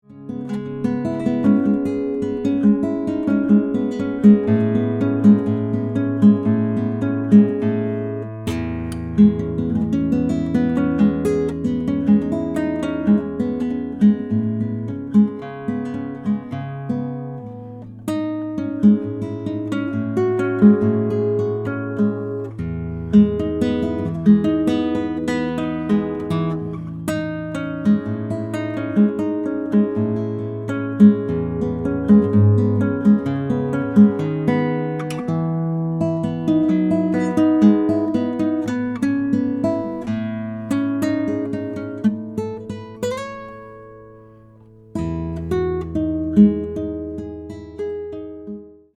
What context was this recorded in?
Here is a short recording of nylon acoustic guitar that I made with a spaced pair of MK-220s in cardioid pattern.